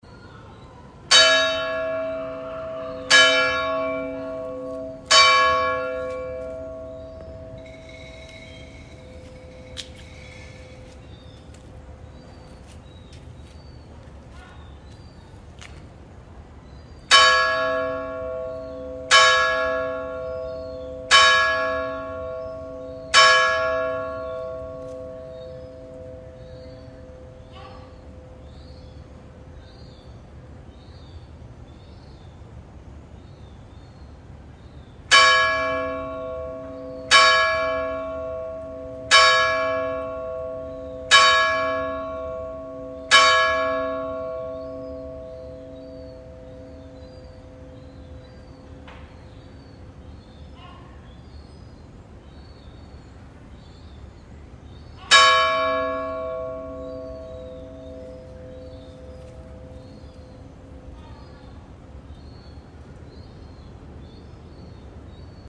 The Ave Maria Bell should ring at … well… the calendar says 17:30, but that could be wrong.
Here it is (you may hear a seagull or two):
Ave_Maria_Trinita.mp3